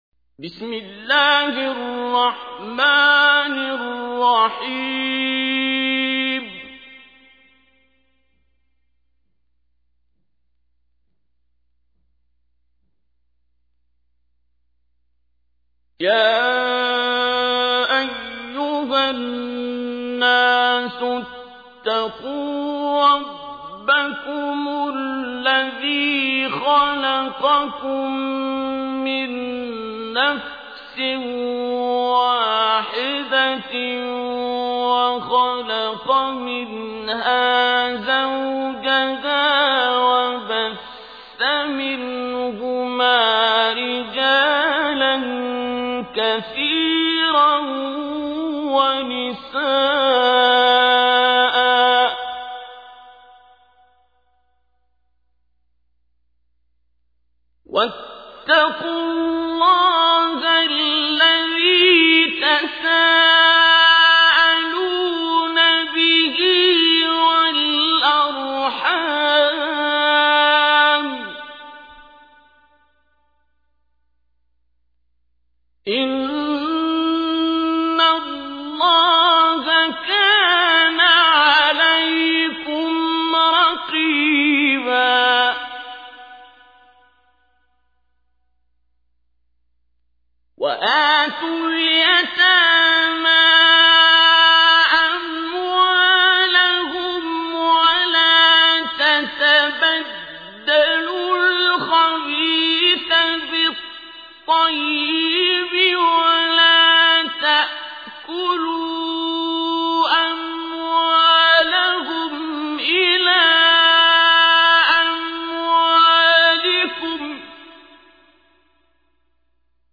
تحميل : 4. سورة النساء / القارئ عبد الباسط عبد الصمد / القرآن الكريم / موقع يا حسين